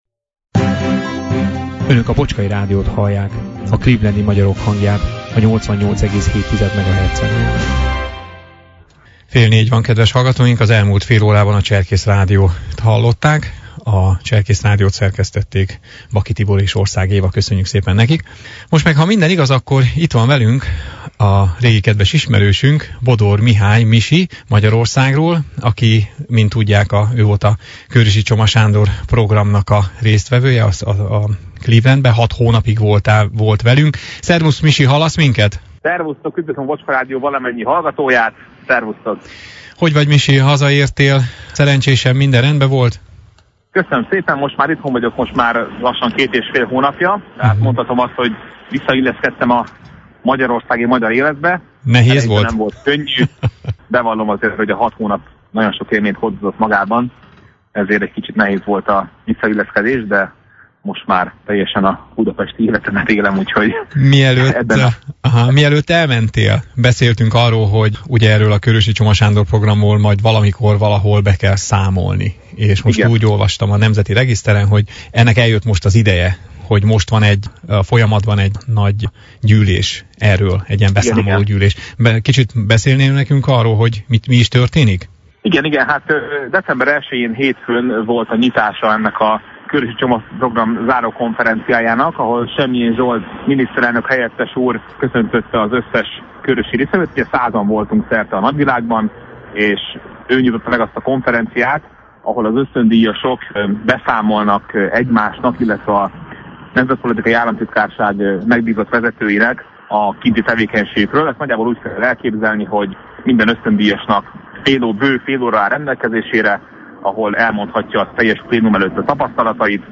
Telefonon elértük őt Budapesten, ahol a KCSP s ösztöndíjasok kötelező beszámoló-kiértékelő gyűlésén van túl. Erről beszélgettünk vele vasárnap.